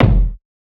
Kicks
KICKINFAMY.wav